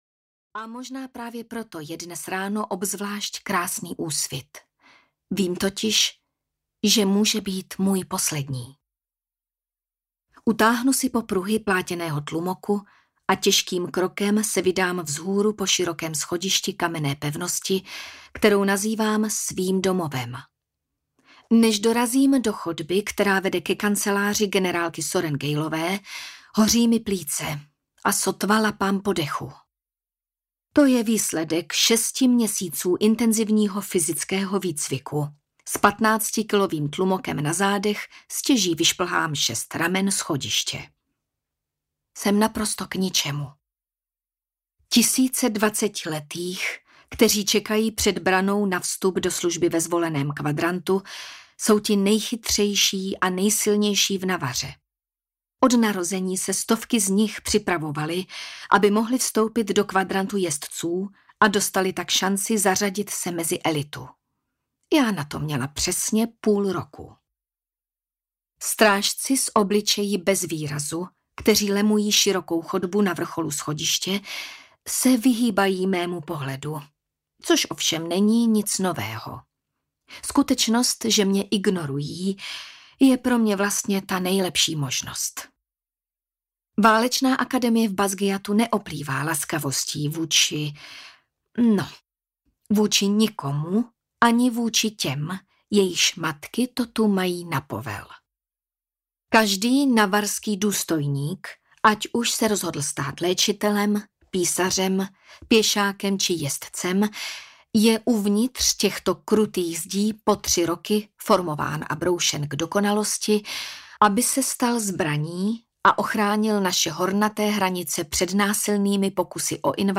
Čtvrté křídlo audiokniha
Ukázka z knihy